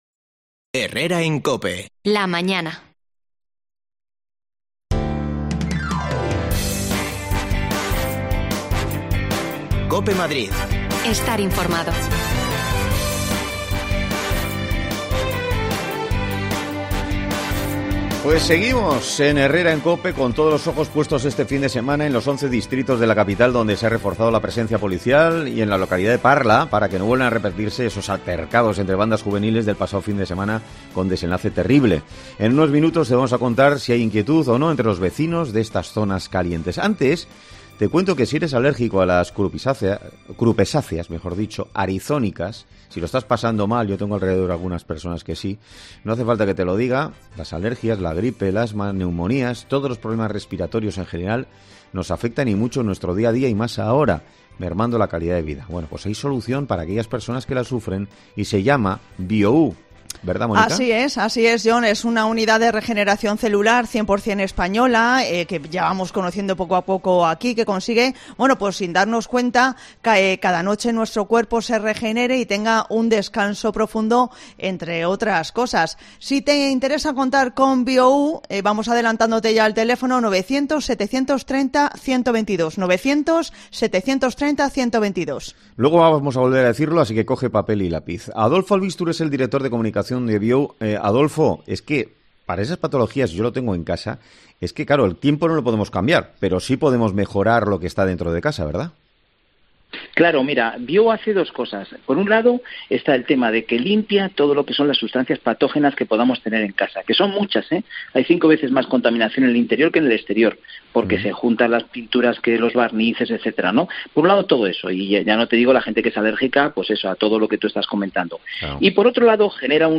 AUDIO: Madrid se blinda este fin de semana para evitar peleas entre bandas juveniles. Nos acercamos a Usera, uno de los puntos calientes
Las desconexiones locales de Madrid son espacios de 10 minutos de duración que se emiten en COPE , de lunes a viernes.